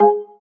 TouchpadSound_error.wav